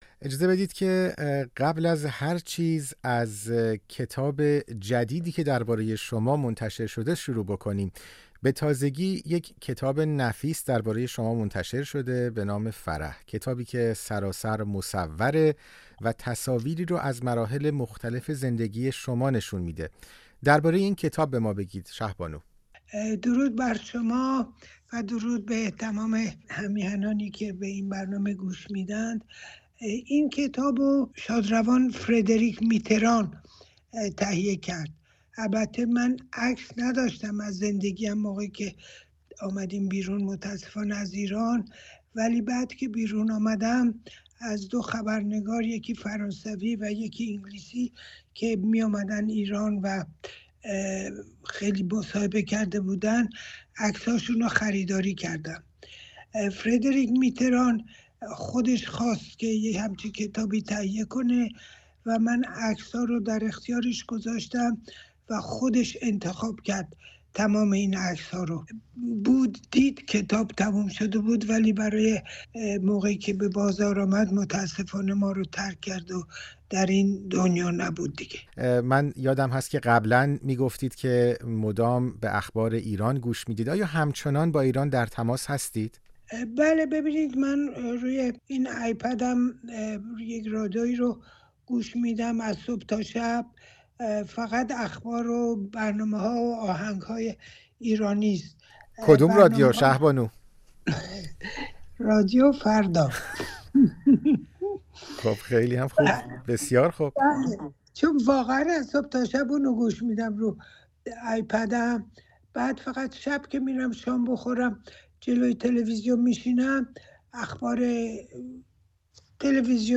گفت‌وگوی اختصاصی با شهبانو فرح پهلوی